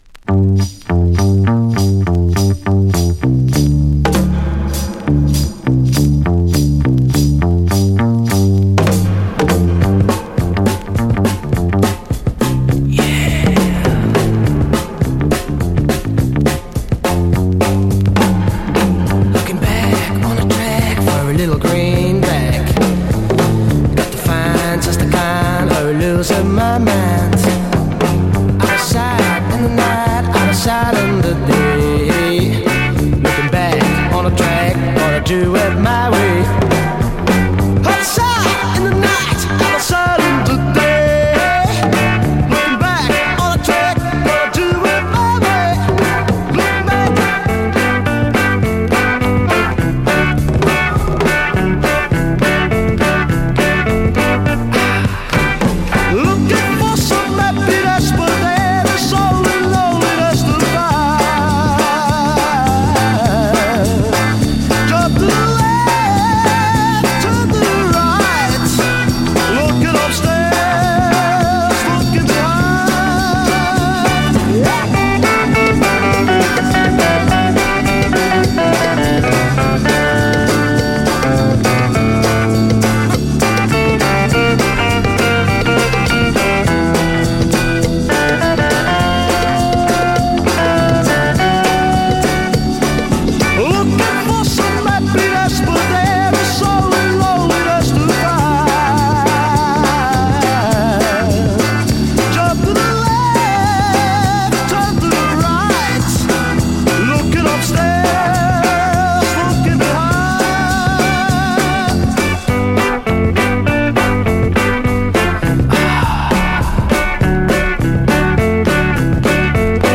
Mod / Freak Beat holland